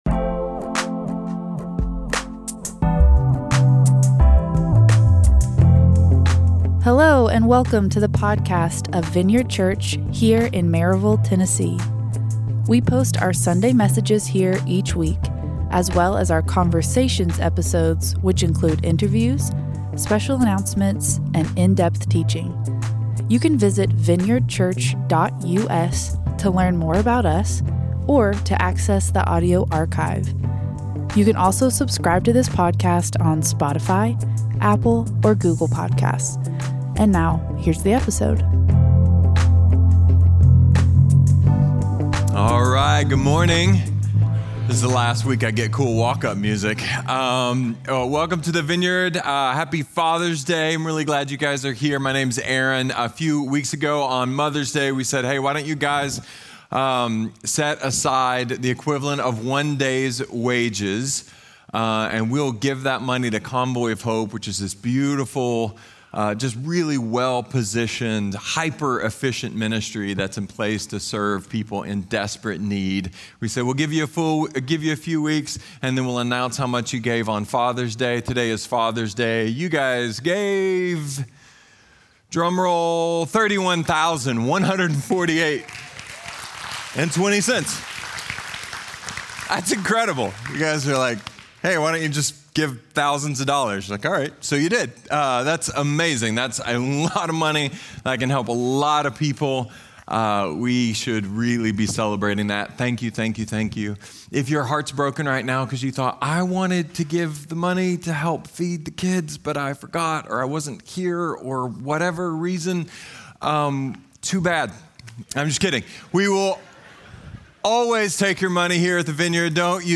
A sermon about grace, identity and home.